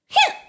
daisy_punch_wah.ogg